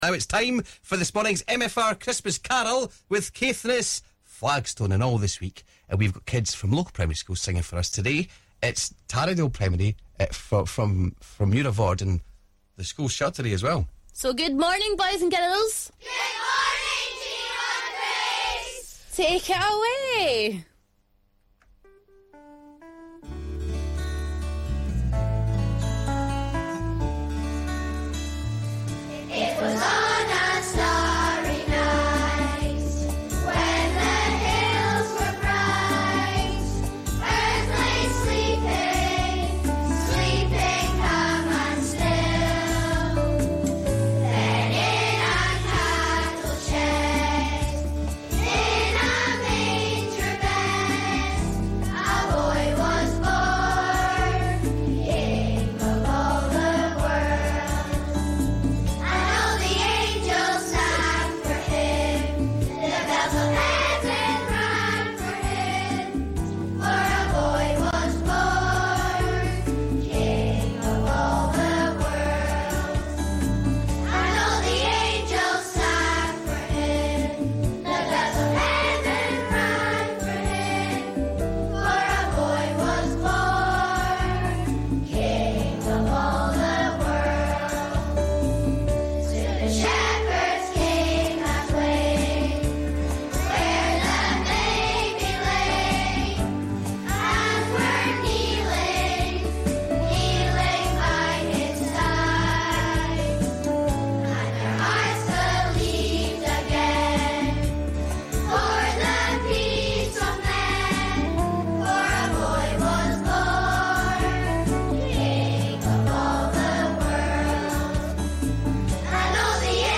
This Christmas we have 5 local primary school choirs performing a Christmas song on MFR all thanks to Caithness Flagstone.